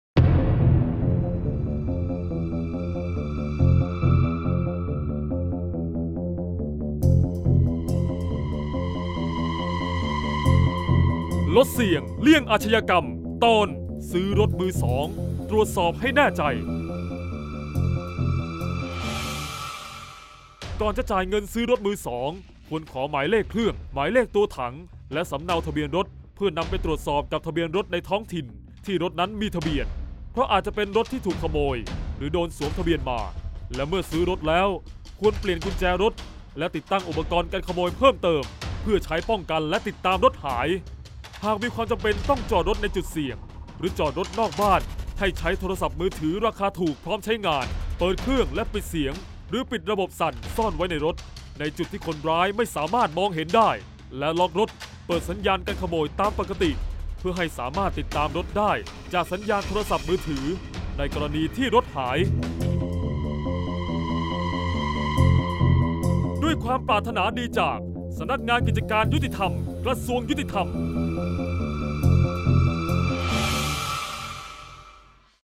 เสียงบรรยาย ลดเสี่ยงเลี่ยงอาชญากรรม 41-รถมือสองต้องระวัง